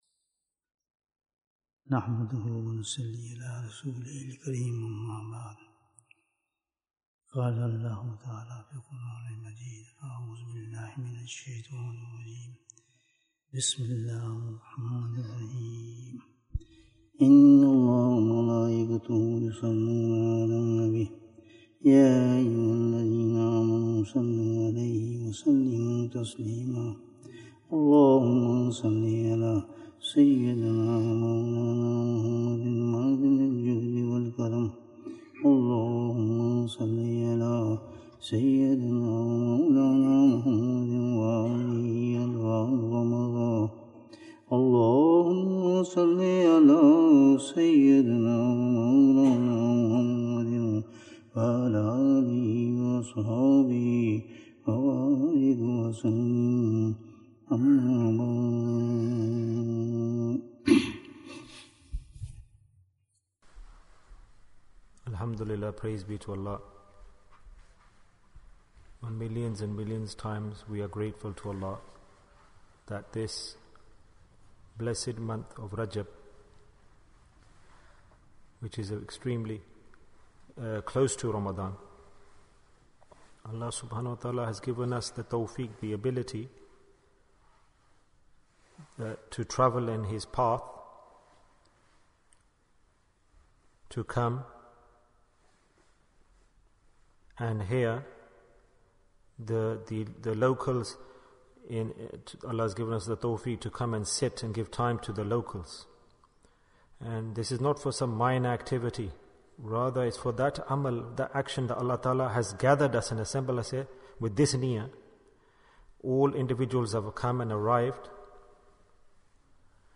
Bayan in Bradford Majlis-e-Dhikr Bayan, 50 minutes4th February, 2023